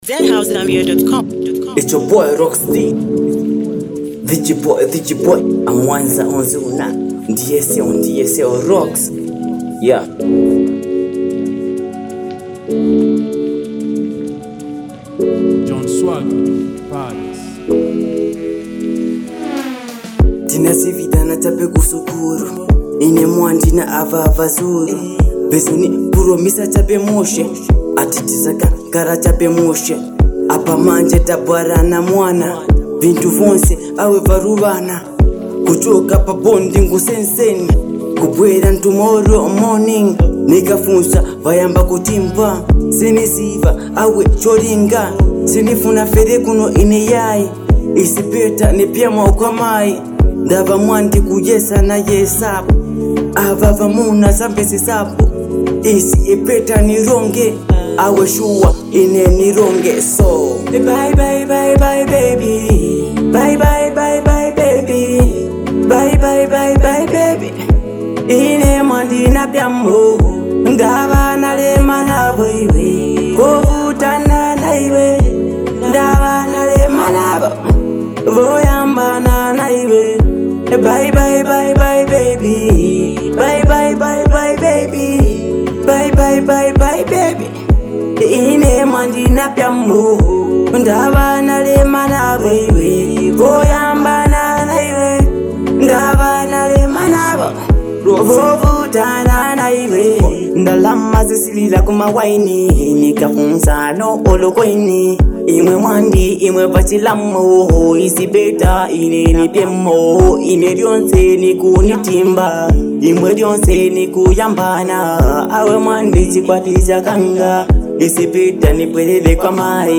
bold, confident record